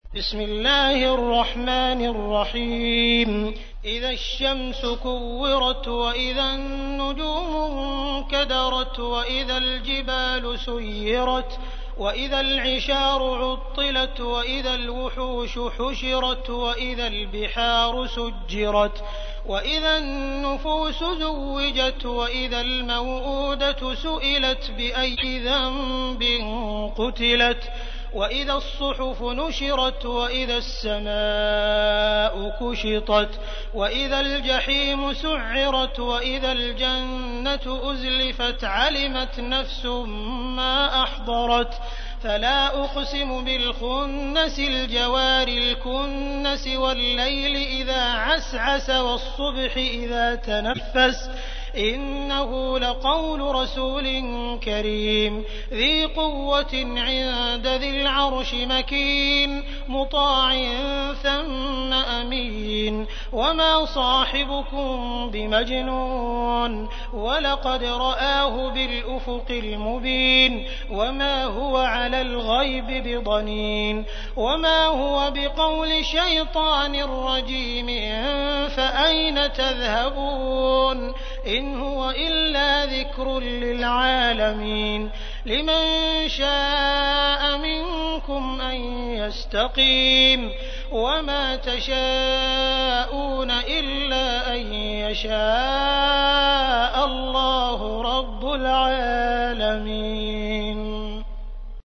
تحميل : 81. سورة التكوير / القارئ عبد الرحمن السديس / القرآن الكريم / موقع يا حسين